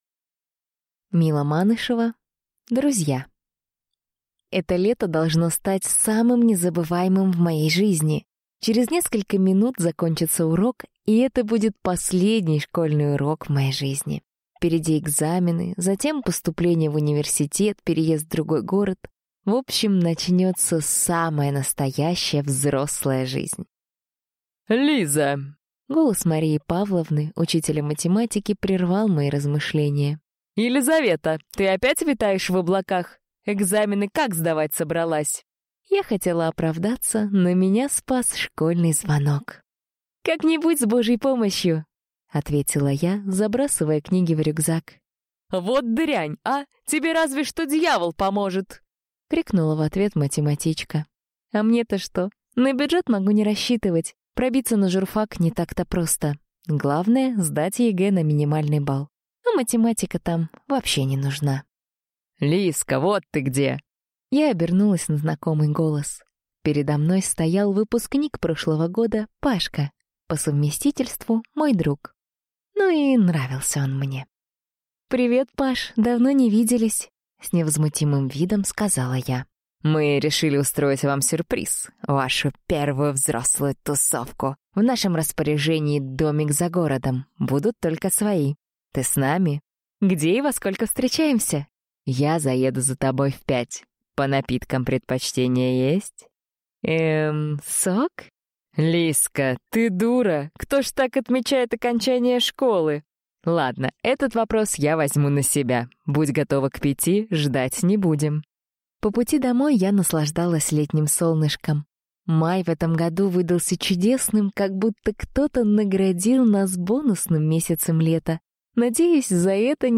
Аудиокнига Друзья | Библиотека аудиокниг